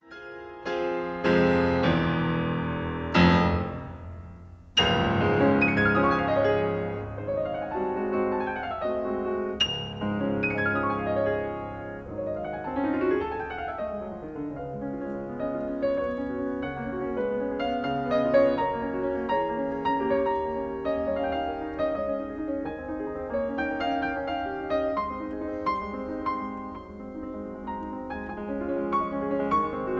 in B flat minor
piano
1959 stereo recording